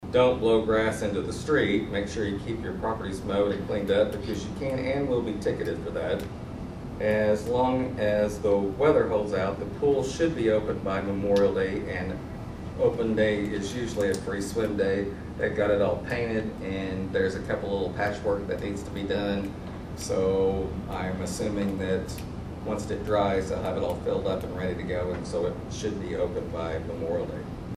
In addition at the Tuesday night meeting, Mayor Pollard gave updates including the annual reminders…